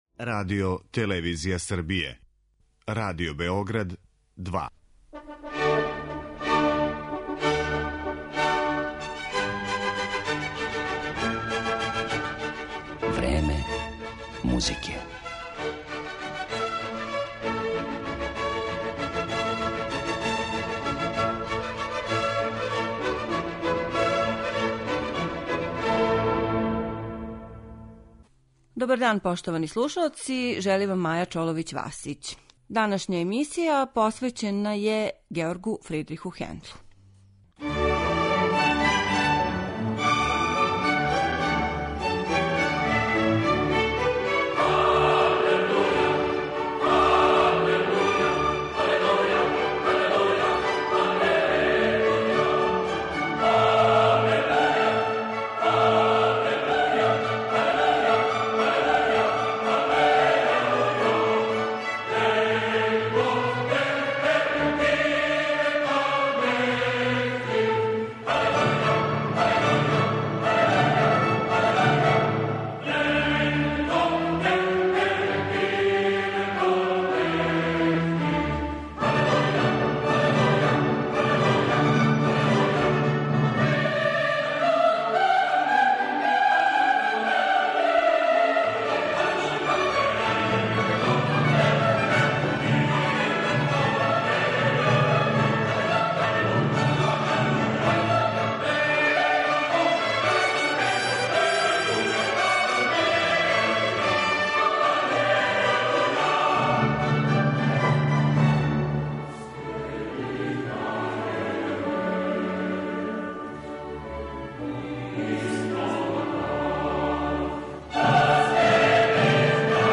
оркестарске свите
Концерт за харфу